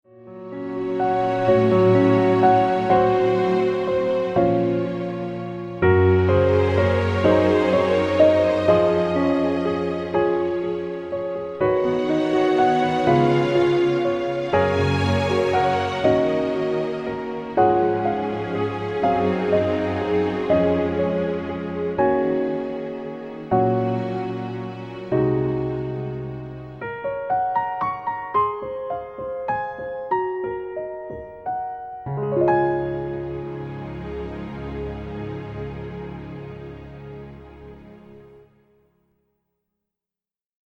Piano - Strings - Medium